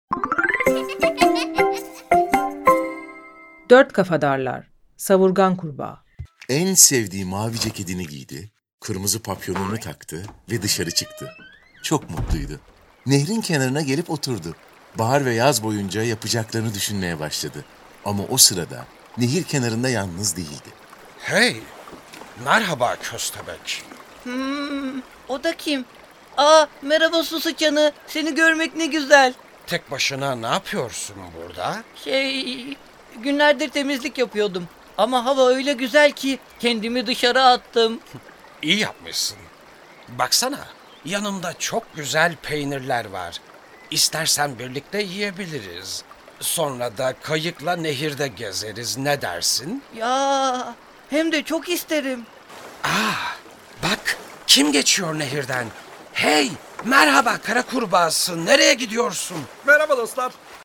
Dört Kafadarlar ve Savurgan Kurbağa Tiyatrosu